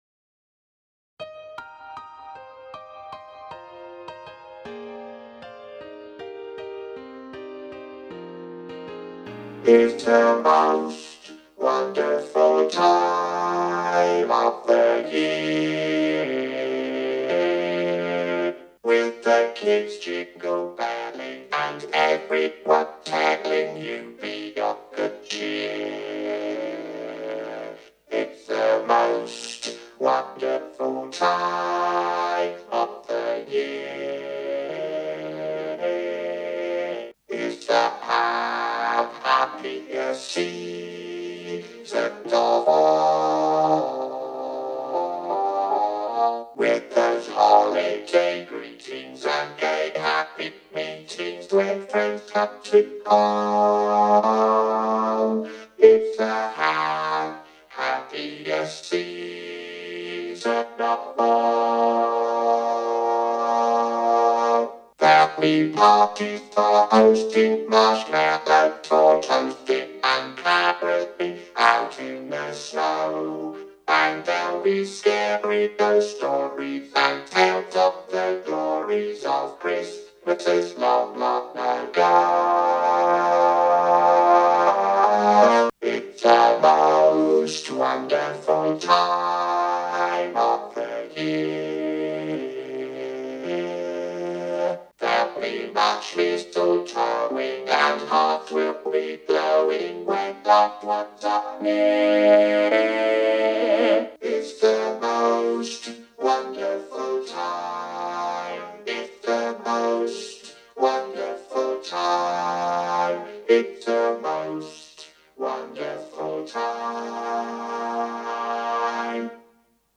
Its-The-Most-Wonderful-Time-Bass.mp3